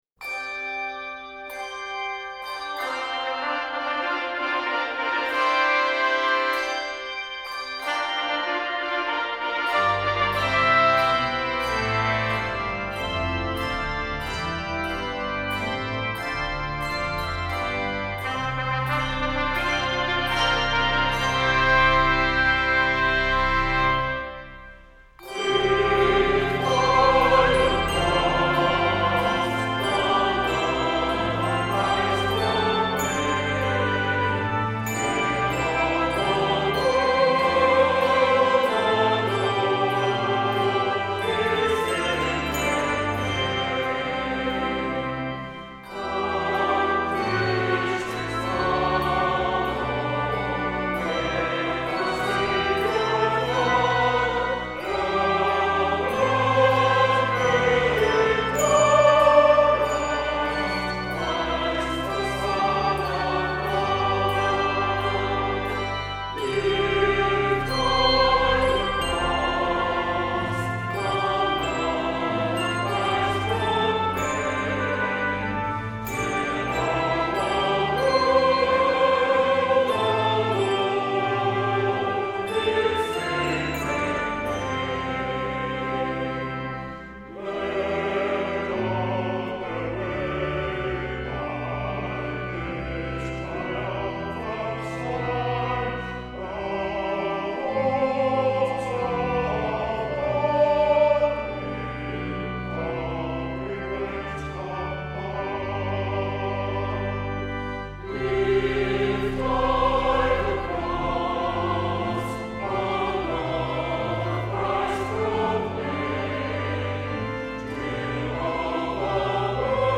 Voicing: 2 Trumpet, 2 T